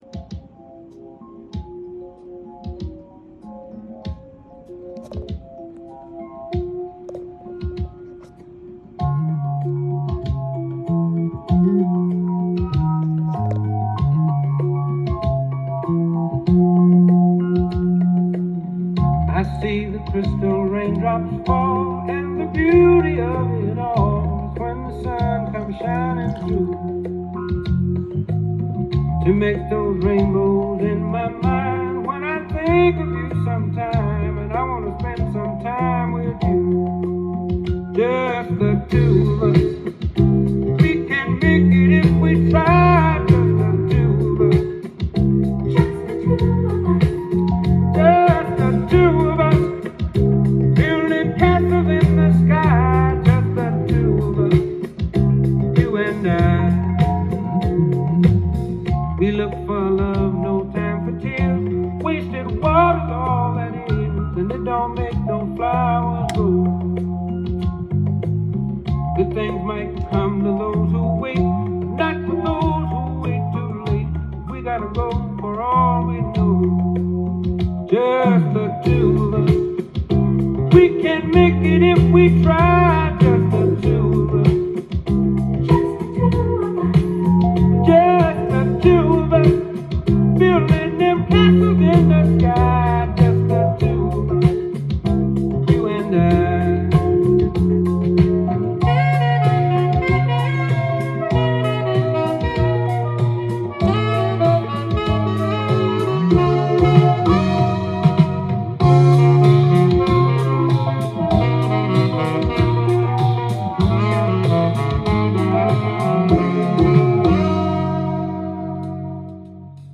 ジャンル：SOUL
店頭で録音した音源の為、多少の外部音や音質の悪さはございますが、サンプルとしてご視聴ください。